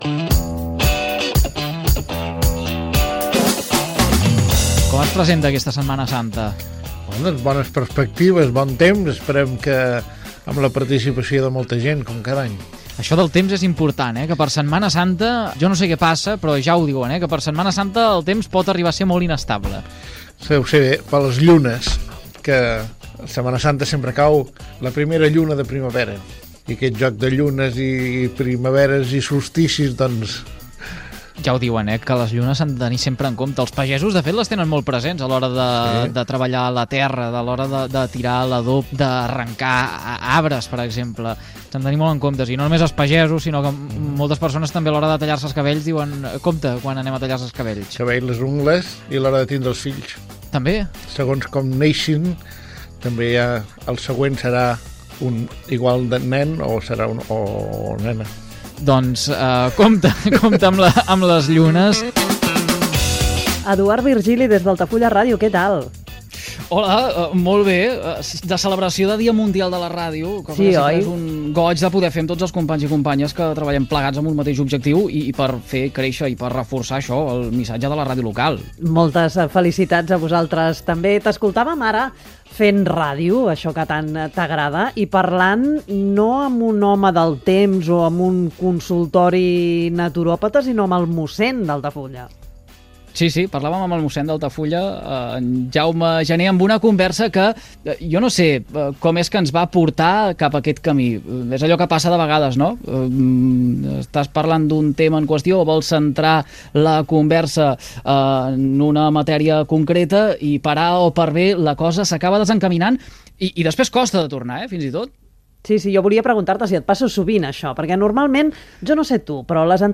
Espai amb motiu del Dia mundial de la ràdio 2022.
Entreteniment